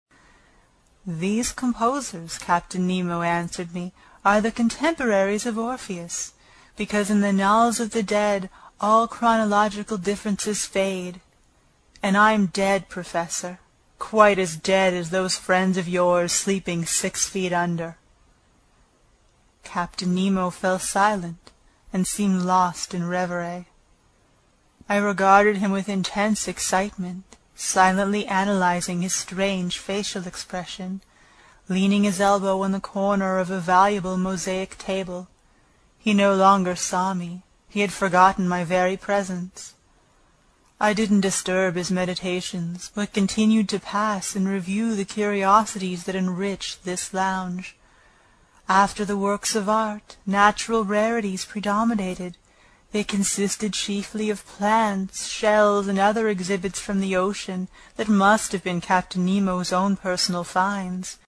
英语听书《海底两万里》第159期 第11章 诺第留斯号(10) 听力文件下载—在线英语听力室
在线英语听力室英语听书《海底两万里》第159期 第11章 诺第留斯号(10)的听力文件下载,《海底两万里》中英双语有声读物附MP3下载